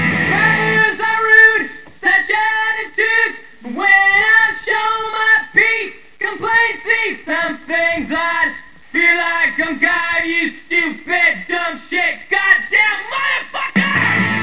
Unfortunately, the clips are not of best quality.